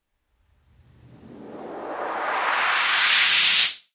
一个声音效果，可以用于很多事情。